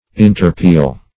Interpeal \In`ter*peal"\